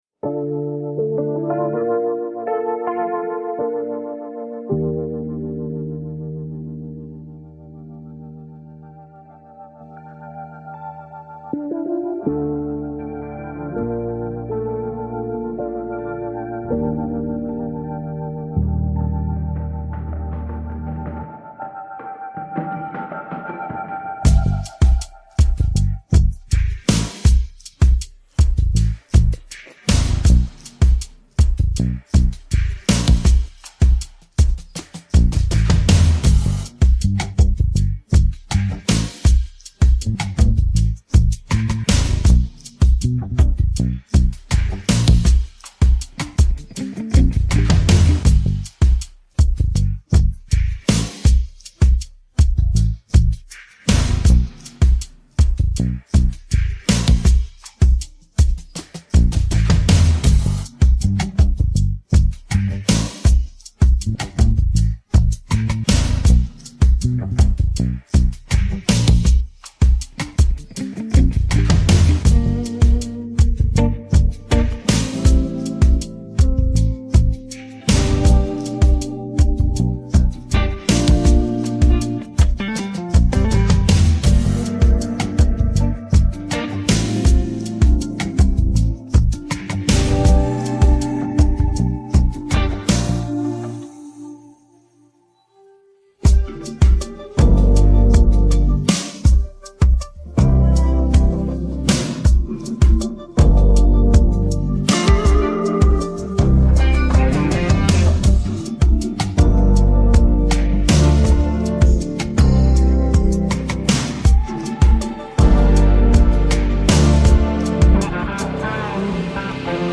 We have the instrumental of the new song right here.